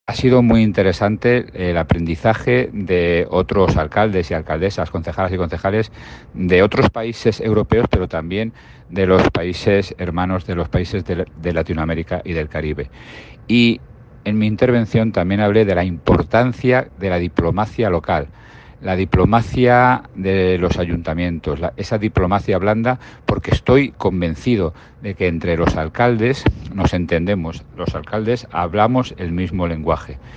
Declaraciones del alcalde José Luis Blanco
Celebrado este jueves y viernes en Barcelona